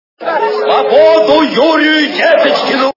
» Звуки » Из фильмов и телепередач » Берегись автомобиля - Свободу Юрию Деточкину
При прослушивании Берегись автомобиля - Свободу Юрию Деточкину качество понижено и присутствуют гудки.